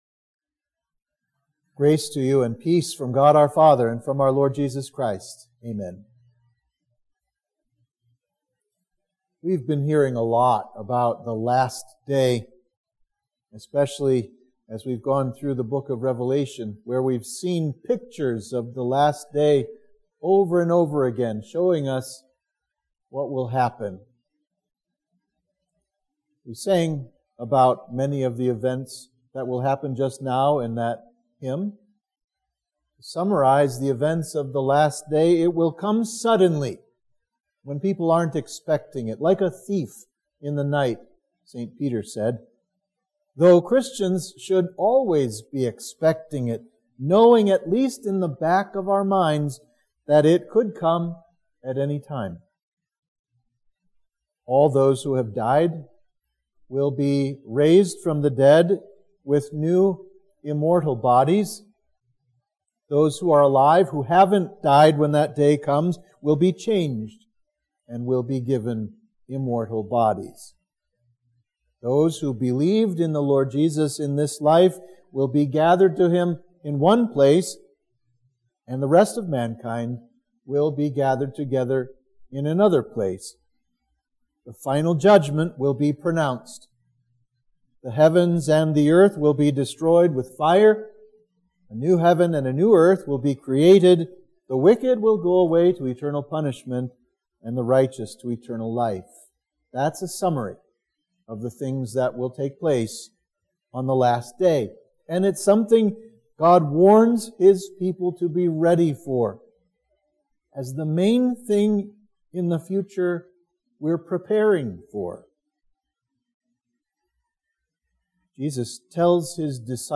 Sermon for Trinity 26